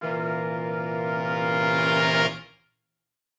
Index of /musicradar/gangster-sting-samples/Chord Hits/Horn Swells
GS_HornSwell-C7b2sus4.wav